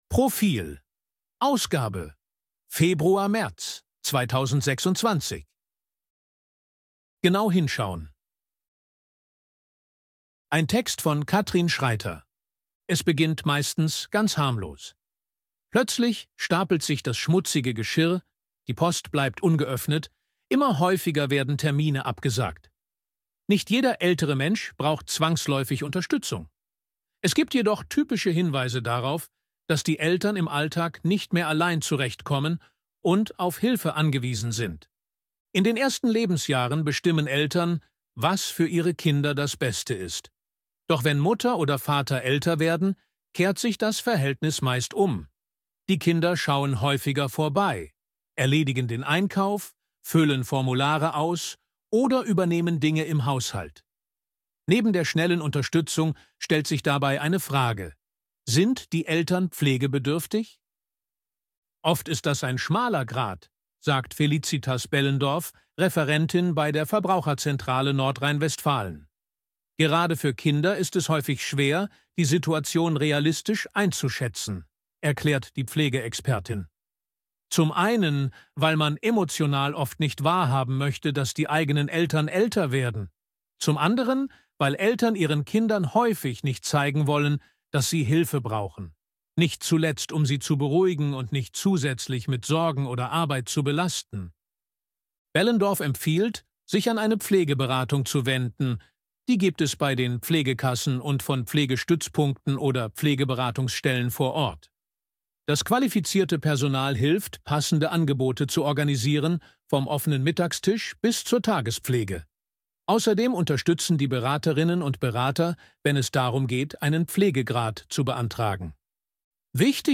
ElevenLabs_261_KI_Stimme_Mann_Service_Leben.ogg